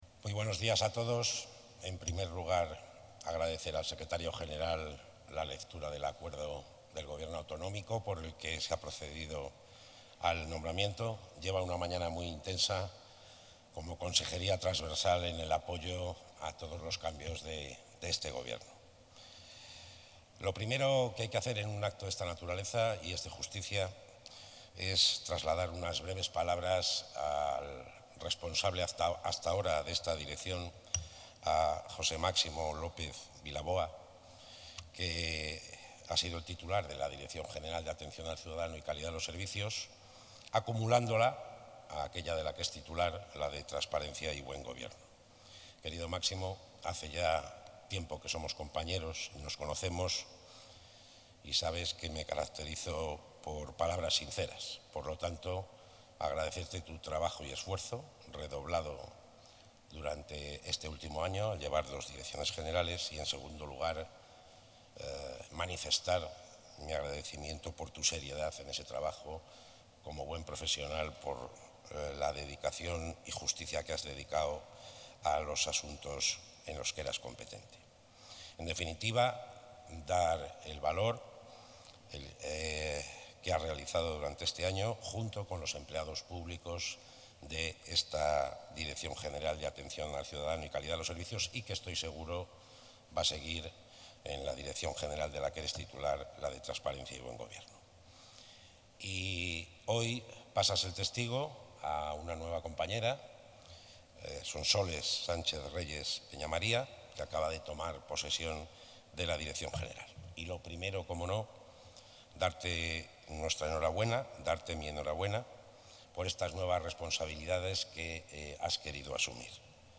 Intervención del consejero de la Presidencia.
El consejero de la Presidencia, Luis Miguel González Gago, ha presidido esta mañana el acto de toma de posesión de la nueva directora general de Atención al Ciudadano y Calidad de los Servicios, Sonsoles Sánchez-Reyes Peñamaría.